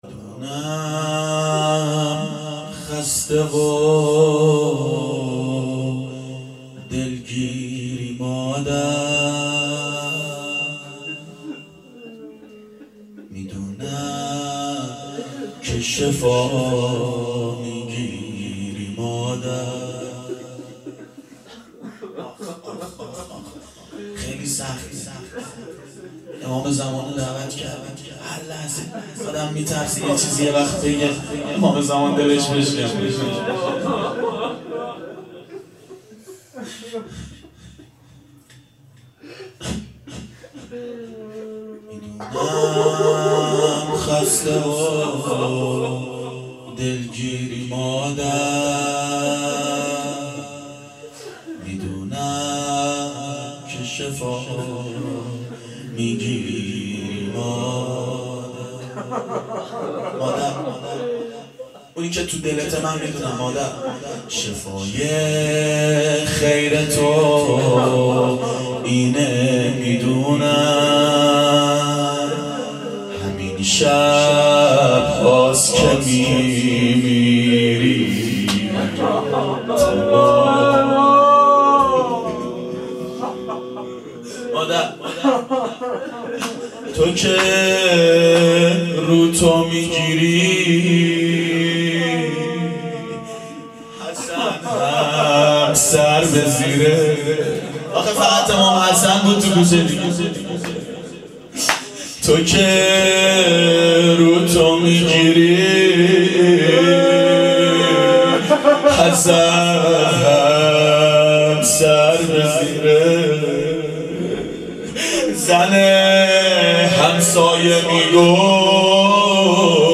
روضه حضرت زهرا و امام حسین علیهماالسلام
شب شهادت حضرت زهرا سلام الله علیها 1389 هیئت عاشقان اباالفضل علیه السلام
02-روضه-حضرت-زهرا-و-امام-حسین-ع.mp3